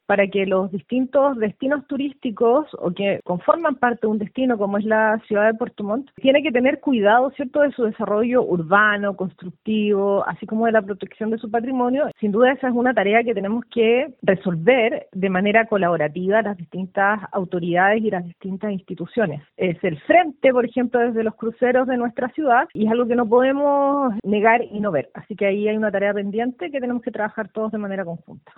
La directora regional de Sernatur, Claudia Renedo, afirmó que este es un tema que debe ser resuelto en la capital regional.